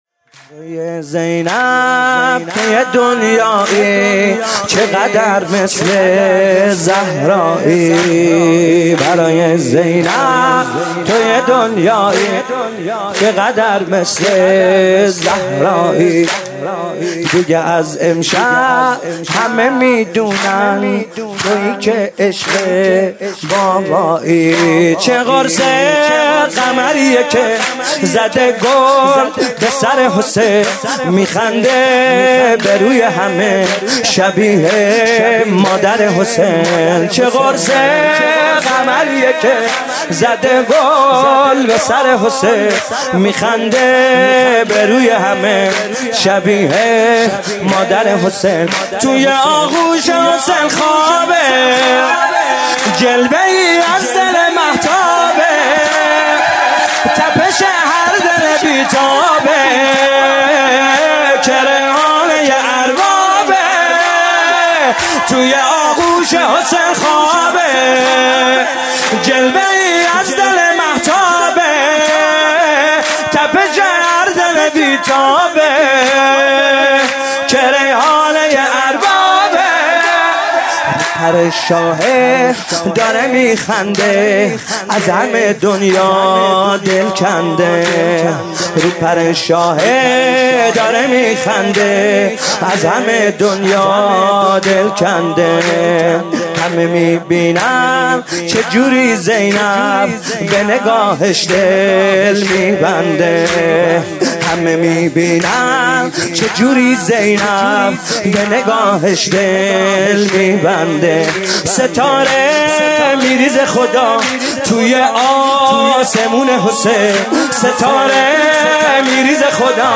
سرود - برای زینب تو یه دنیایی